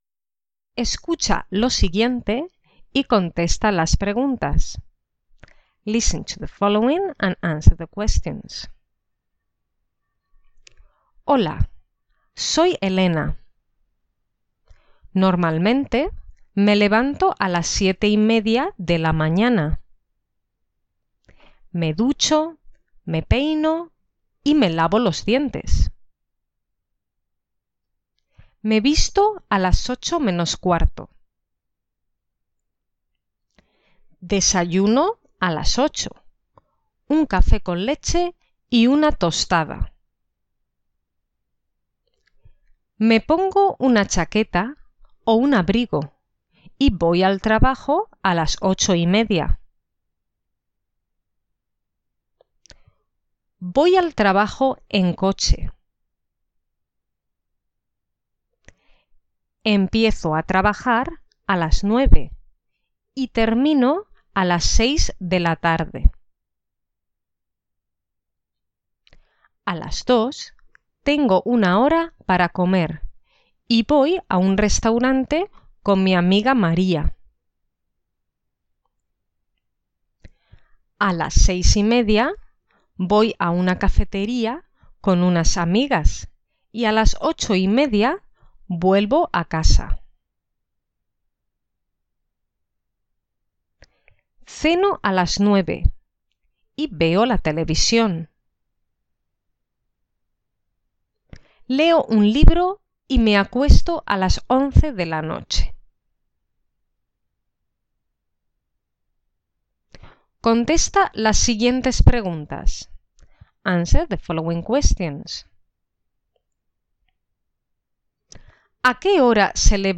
Review
2.-Revision-Reflexive-verbs-Part-3.mp3